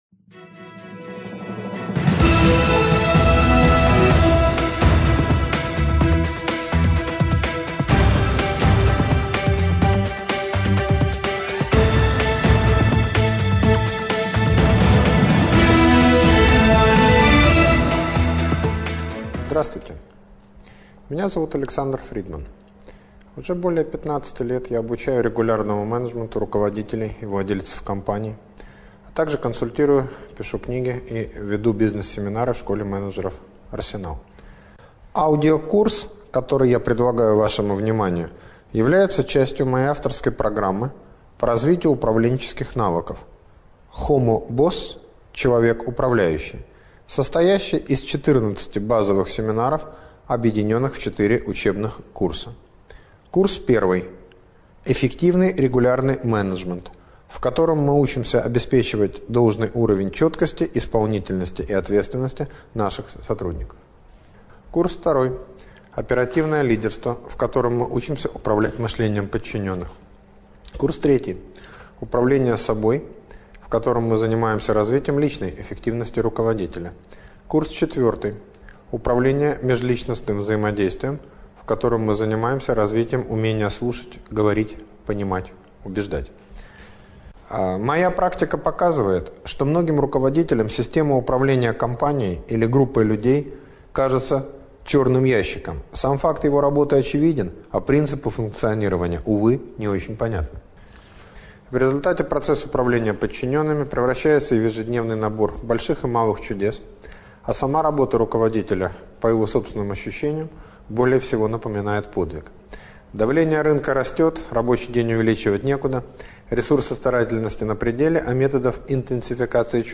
Этот уникальный авторский семинар, проходящий в формате мастер-класса, позволит вам сконфигурировать корпоративные отношения в соответствии со своими предпочтениями и задачами организации, поможет избавиться от типичных управленческих иллюзий, которые мешают вам добиться от подчиненных эффективной работы с полной отдачей.